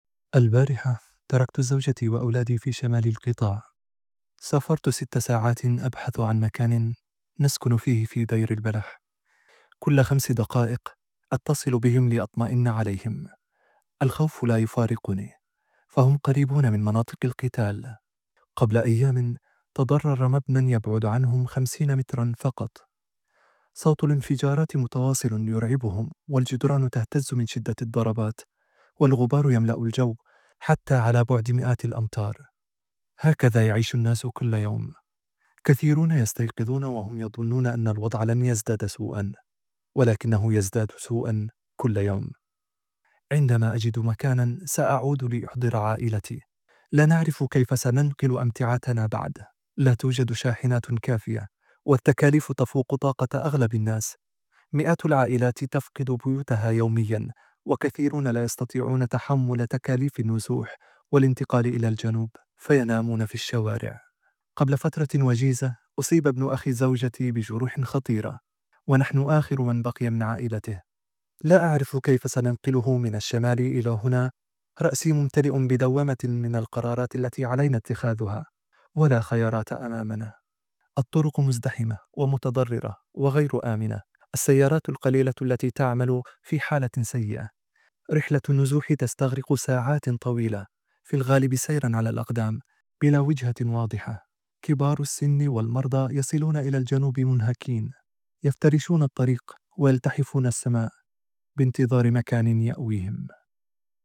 شهادة حيّة